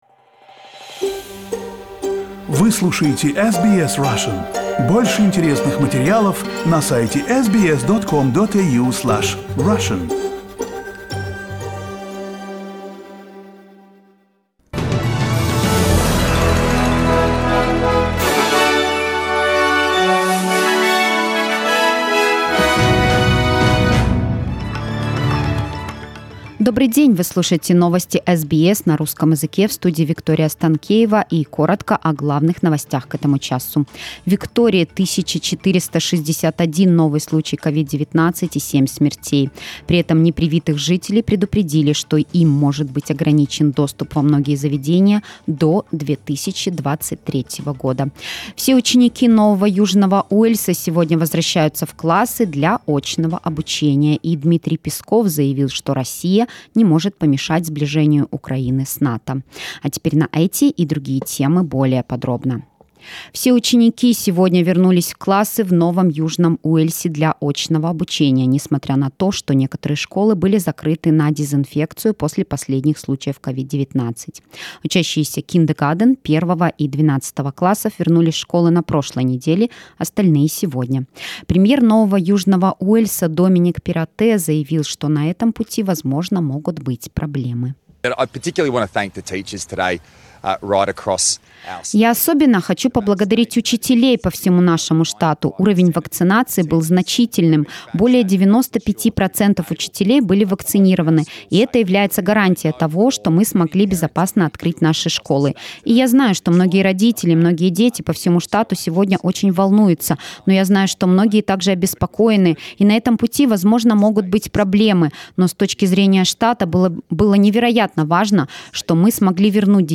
SBS news in Russian - 25.10